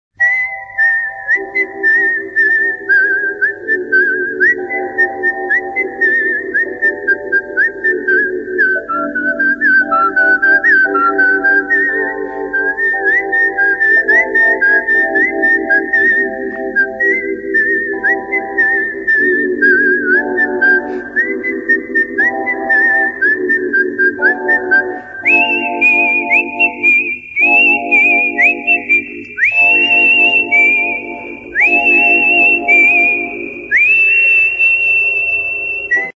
Piskanie